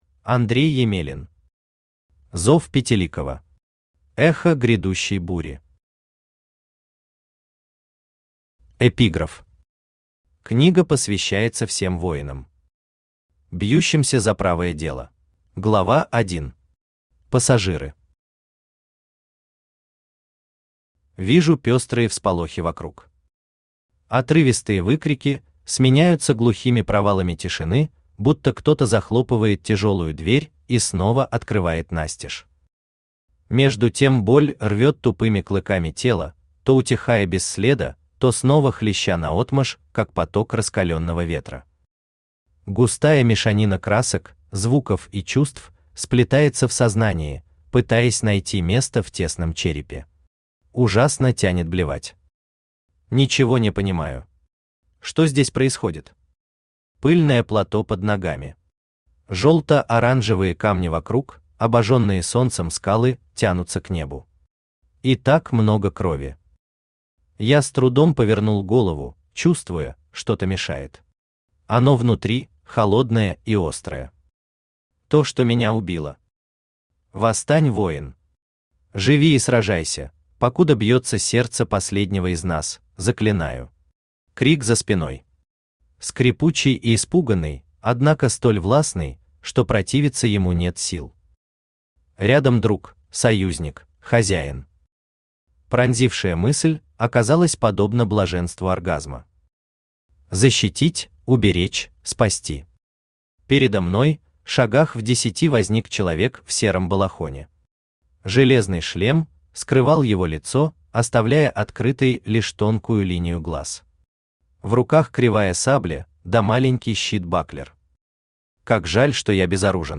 Аудиокнига Зов Пятиликого. Эхо грядущей бури | Библиотека аудиокниг
Эхо грядущей бури Автор Андрей Емелин Читает аудиокнигу Авточтец ЛитРес.